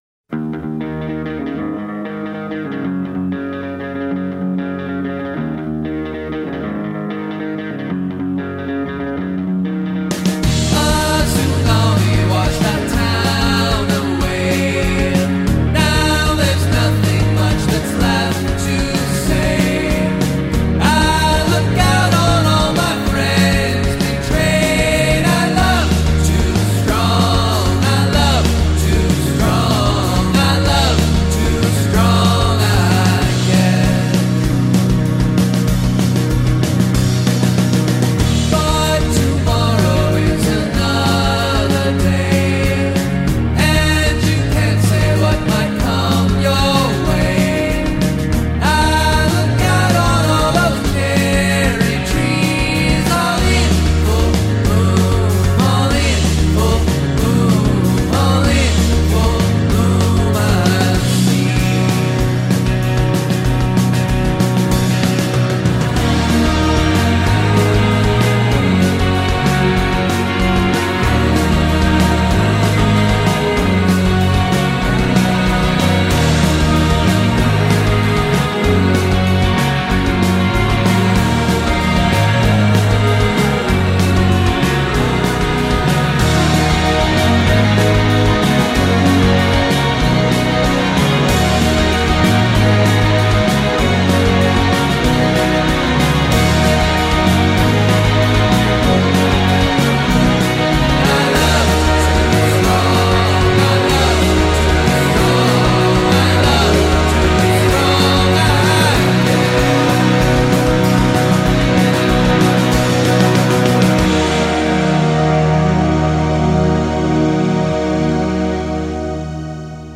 dream-rock